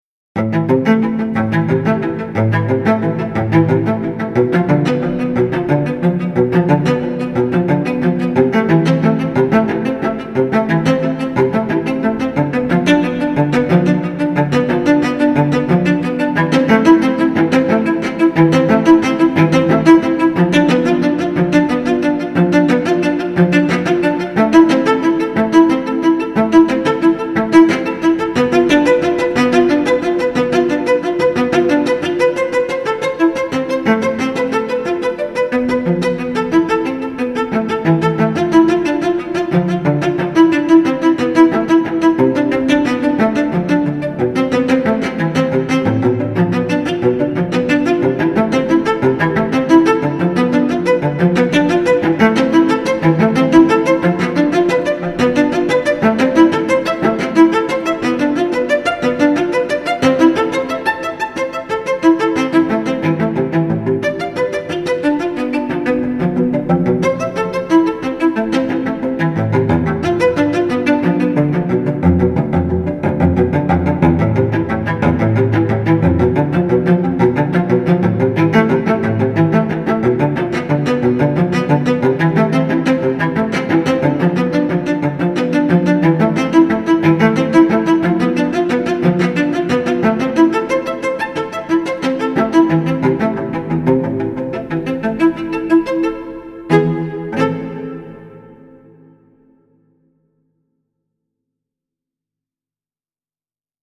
Classical_Cello_Solo.mp3